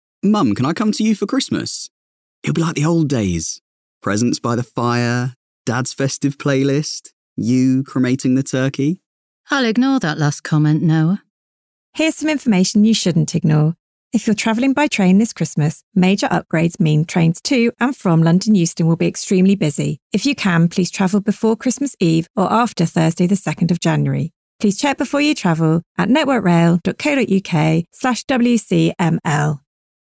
Radio advert